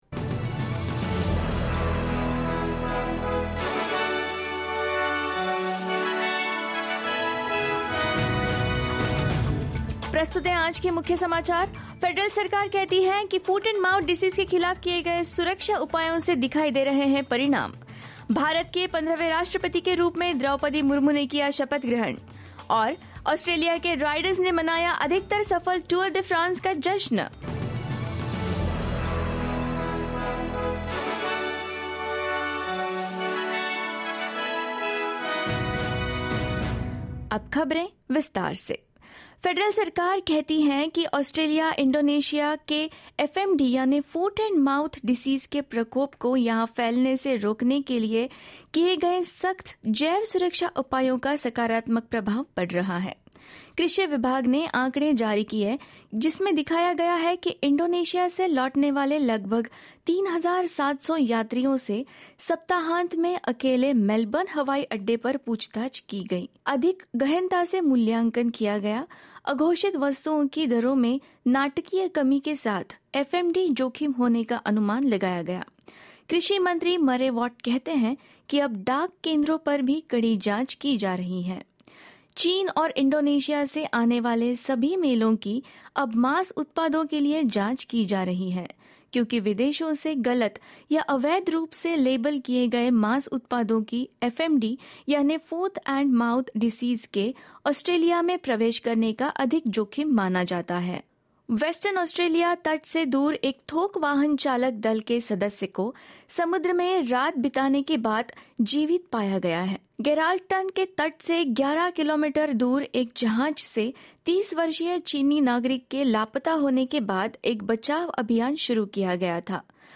In this latest SBS Hindi bulletin: Agriculture minister Murray Watt says stricter biosecurity checks are in place against foot and mouth disease; Draupadi Murmu takes oath as India's 15th President; Australian riders celebrate their performance at Tour de France and more.